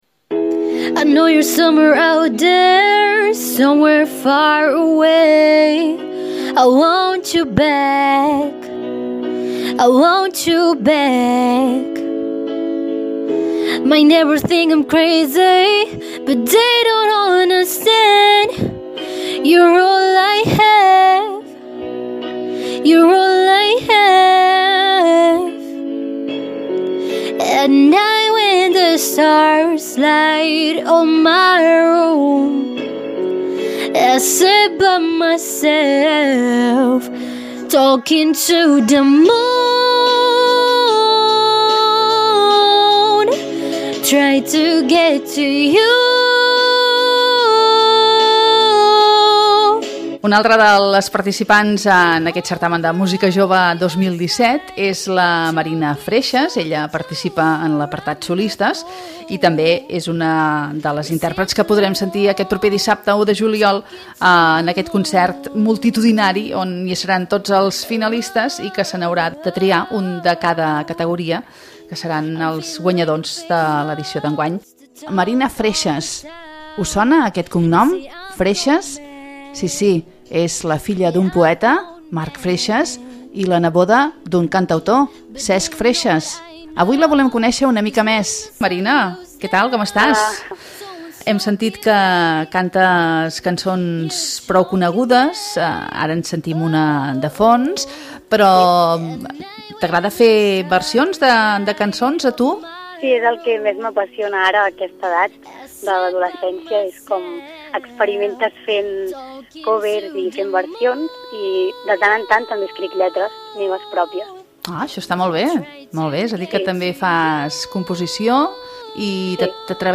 ENTREVISTES CERTÀMEN MÚSICA JOVE 2017